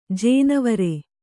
♪ jēnavare